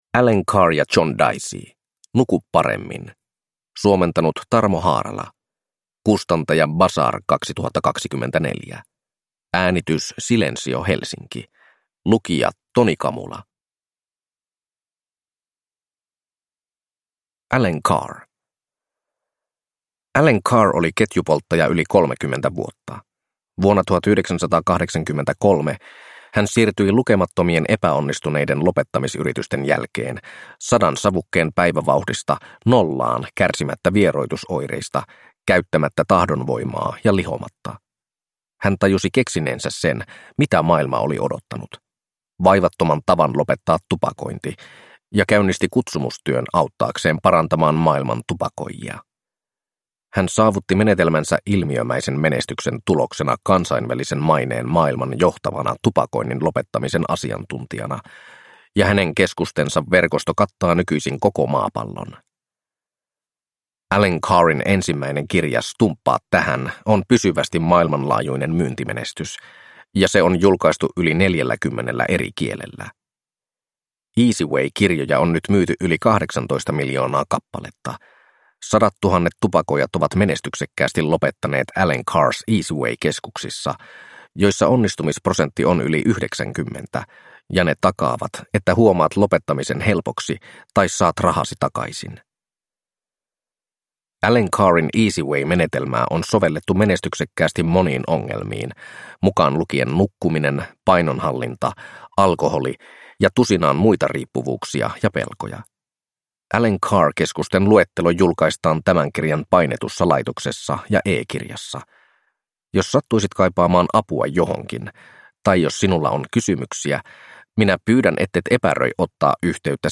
Nuku paremmin! – Ljudbok